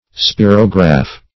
Spirograph \Spi"ro*graph\, n. [L. spirare to breathe + -graph.]